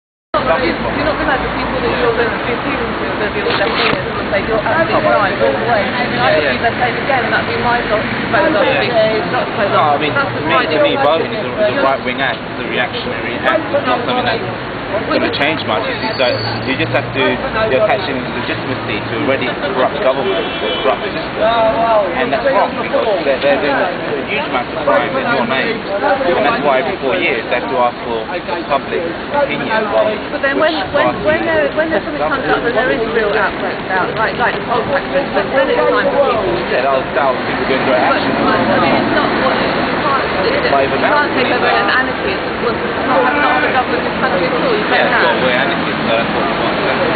Some people came up to us - and made their points, this video is just one of those interactions. 47 seconds.